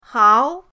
hao2.mp3